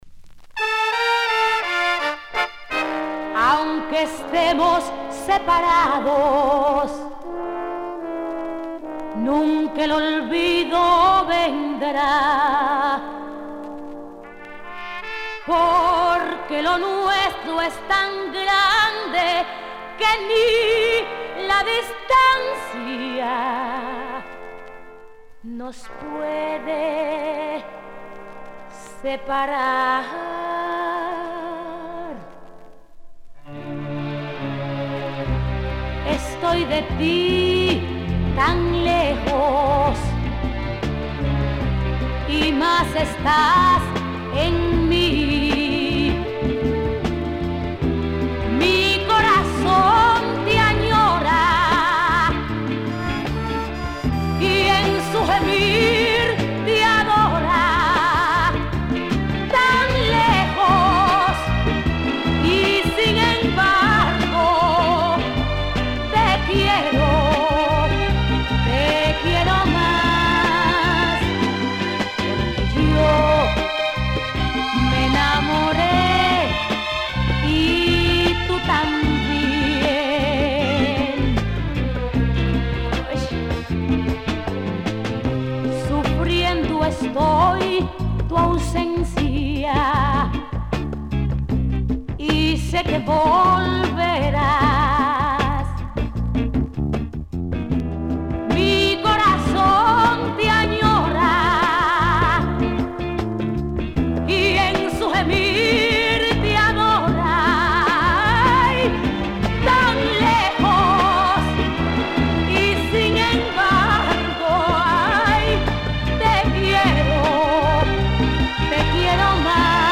Tossed in the B side, which is just as powerful.